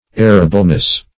Errableness \Er"ra*ble*ness\, n. Liability to error.